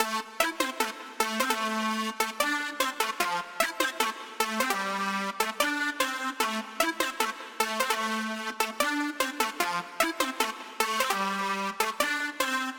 150BPM Lead 03 Amin.wav